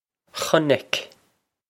Chonaic Khun-ic
This is an approximate phonetic pronunciation of the phrase.